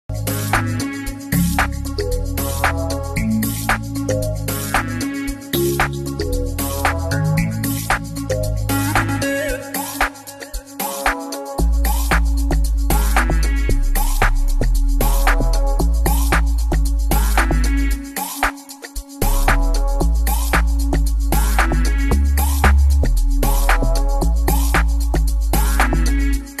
type beat
Amapiano